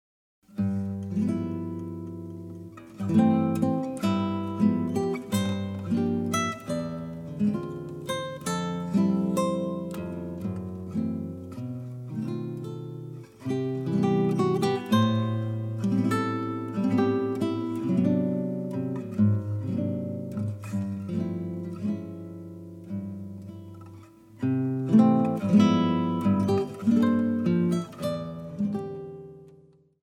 acoustic seven-string guitar
electric guitar, vocals
pedal steel guitar
acoustic bass
drums